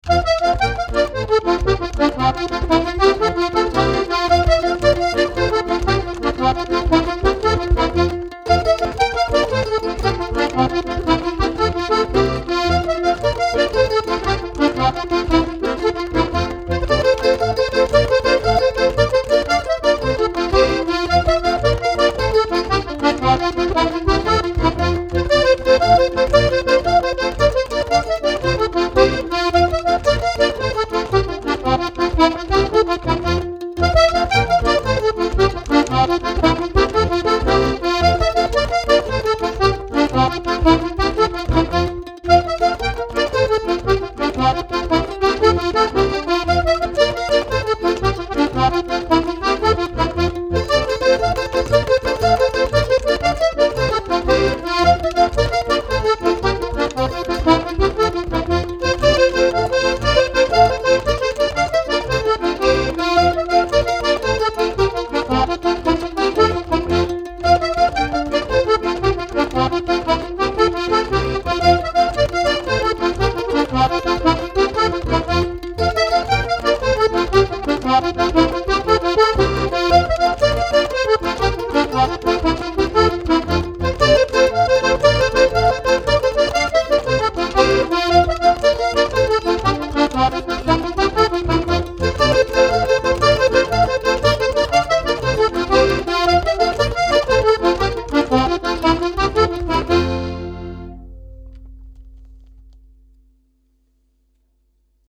trekharmonica speler voor oudhollandsche muziek
Trekharmonica/knopaccordeon & mandoline duo Leuke achtergrond muziek in de sfeer van een Boeren bruiloft met de klompendans en de horlepiep. De diatonische trekharmonica heeft een heel traditioneel geluid, met iets meer ritme dan een gewone accordeon door de trek-en duw beweging die verschillende tonen geeft.
De trekzak wordt begeleid met het mooie rinkelende geluid van de mandoline, een instrument met zeer oude europese wortels.
Geluidsvoorbeelden met diatonische trekzak: